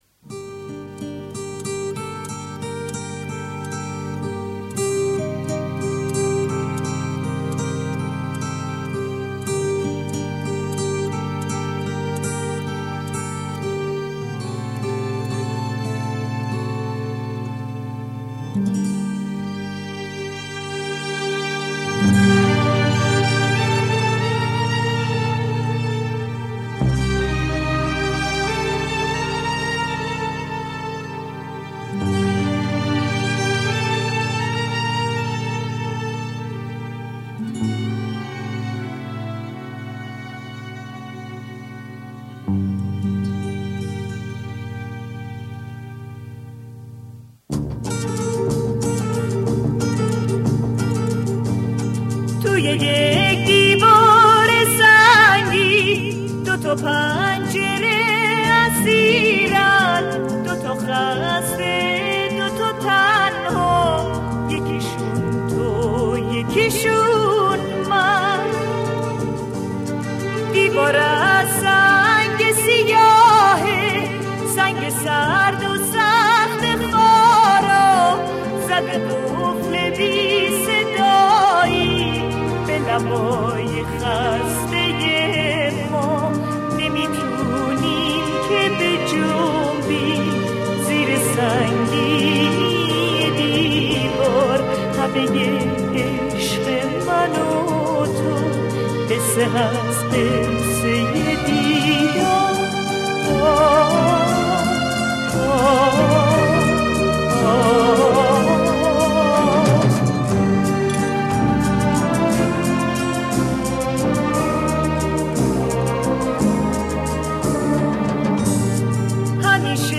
آهنگ قدیمی غمگین